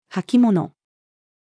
履き物-female.mp3